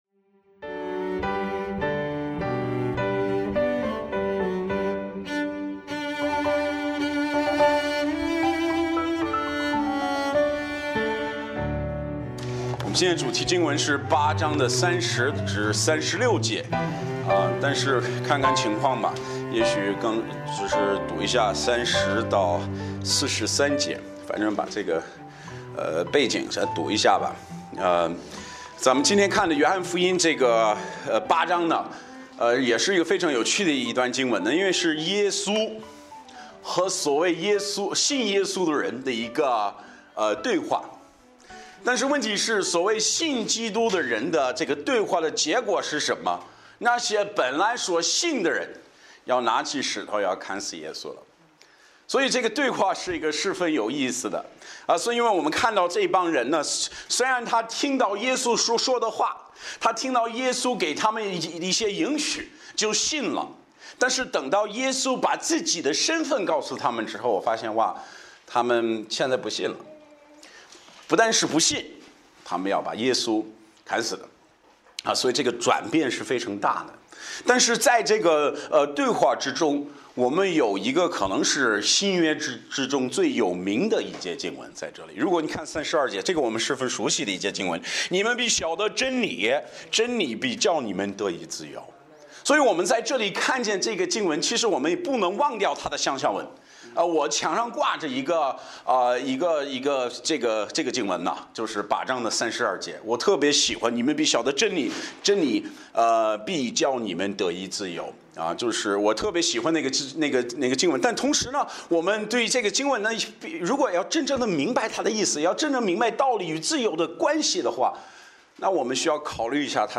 Series: 周日礼拜